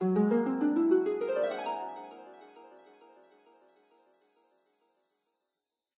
magic_harp_1.ogg